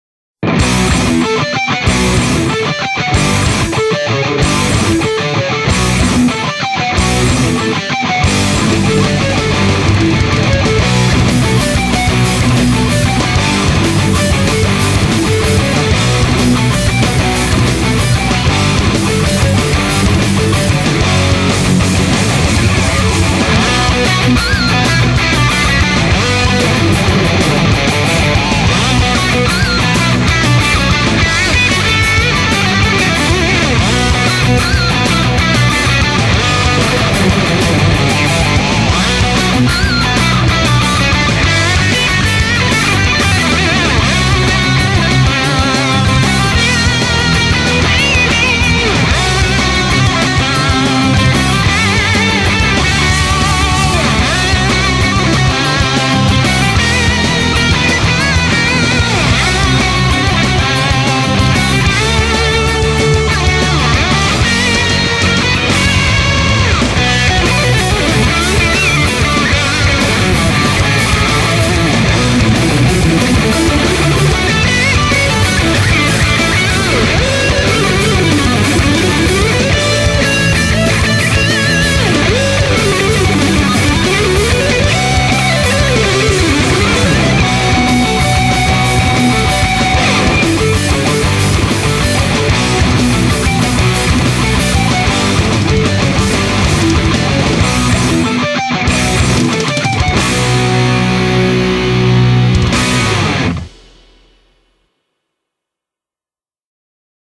BPM188
Audio QualityPerfect (Low Quality)